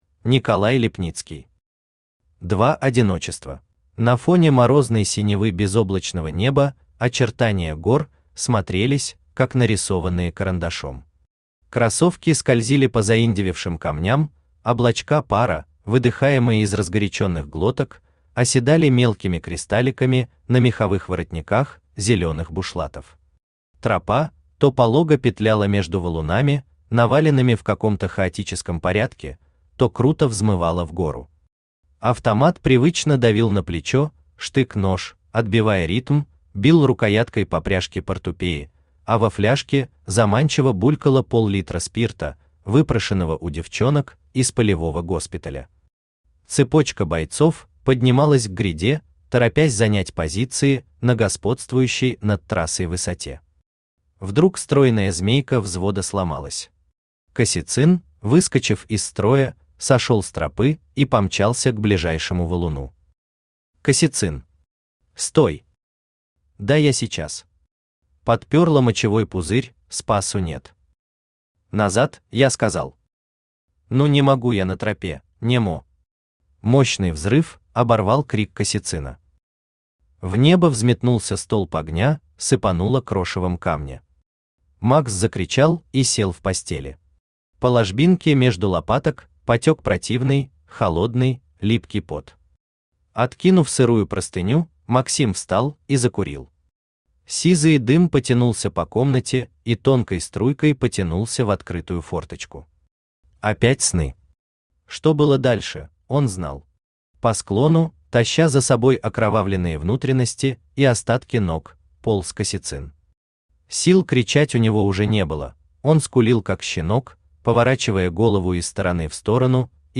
Аудиокнига Два одиночества | Библиотека аудиокниг
Aудиокнига Два одиночества Автор Николай Иванович Липницкий Читает аудиокнигу Авточтец ЛитРес.